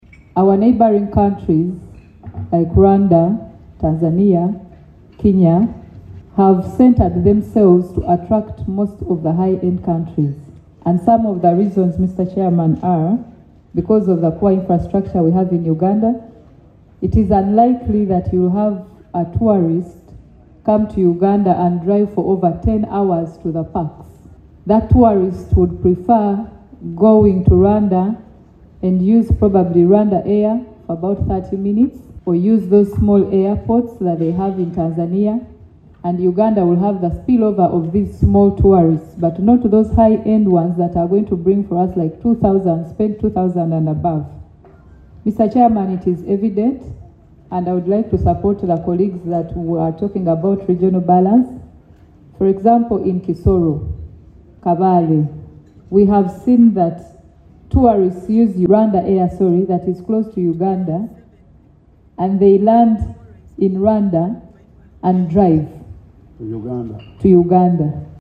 Hon. Nayebale (C) makes her presentation during the meeting